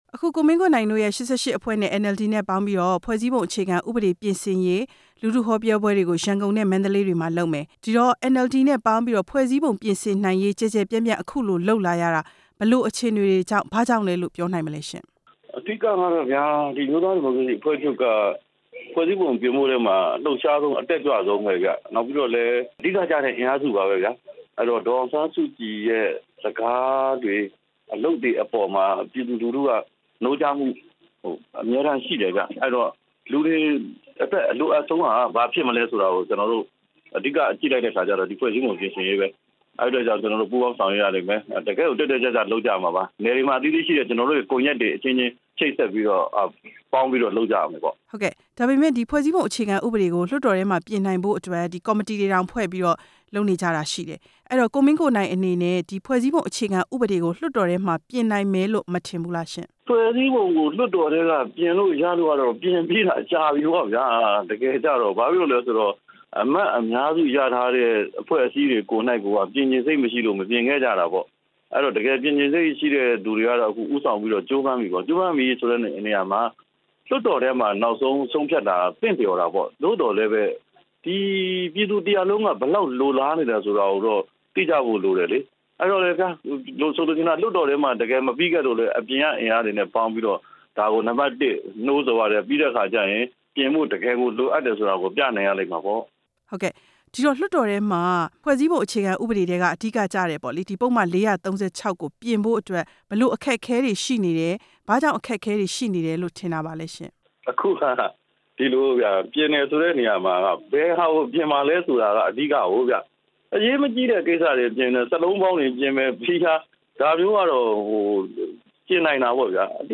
NLD နဲ့ ပူးပေါင်းလှုပ်ရှားရေး ကိုမင်းကိုနိုင်နဲ့ ဆက်သွယ်မေးမြန်းချက်